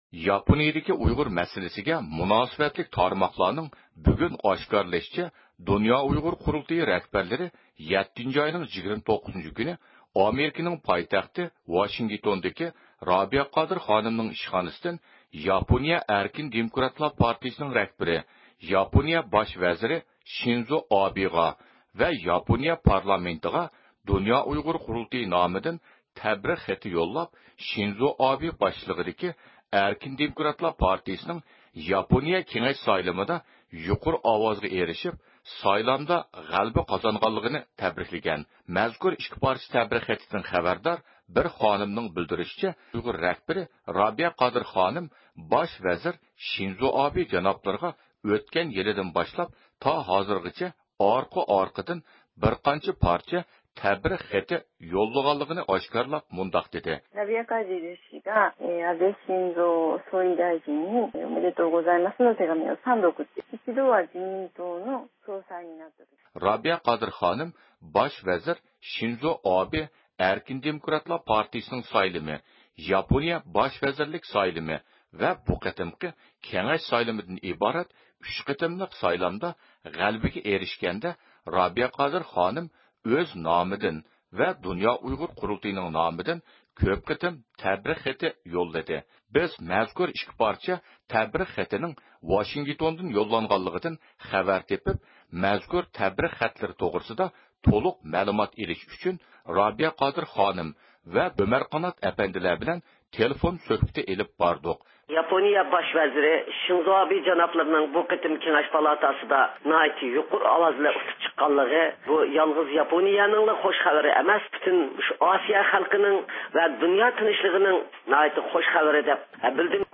بىز ئالدى بىلەن رابىيە قادىر خانىم بىلەن باش ۋەزىر شىنزو ئابېغا يېزىلغان تەبرىك خېتى توغرىسىدا قىسقىچە تېلېفون سۆھبىتى ئېلىپ باردۇق.